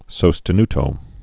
(sōstə-ntō, sô-) Music